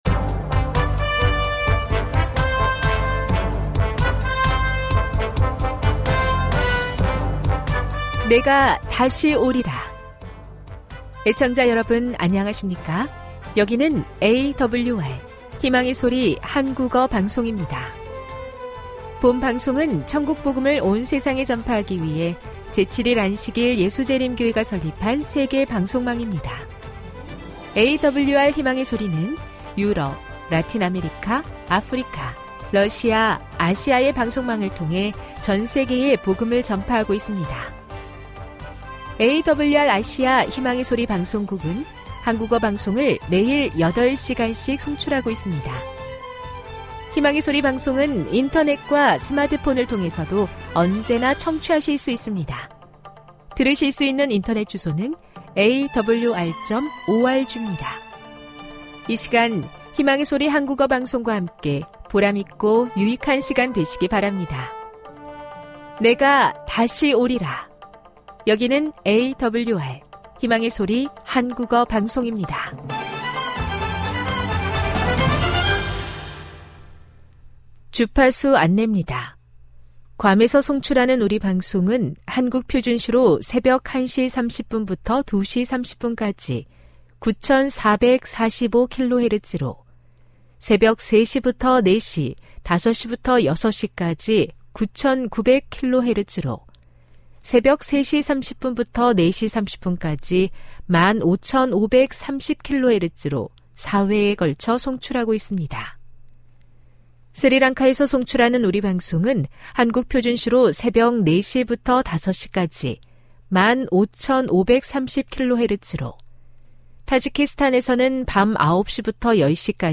1 설교, 명상의오솔길, 생명의 양식 58:08